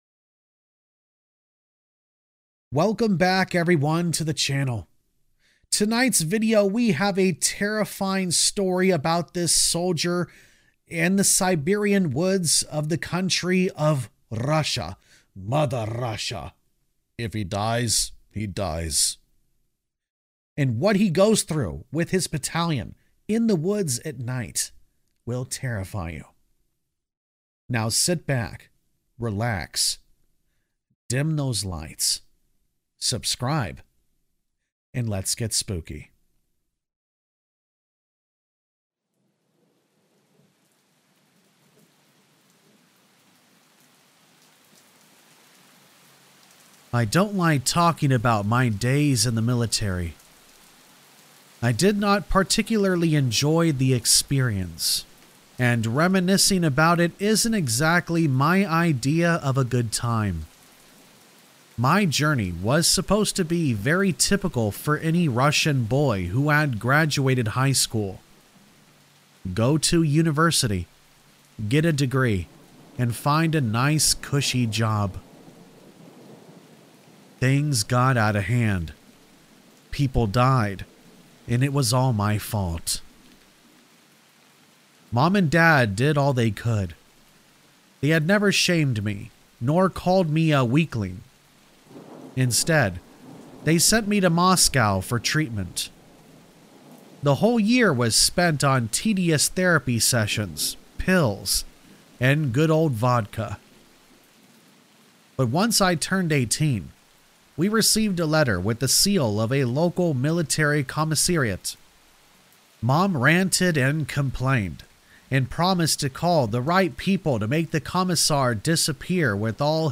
I Used To Be A Soldier. Something Found Us In The Siberian Woods! | Black Screen For Sleep | ASMR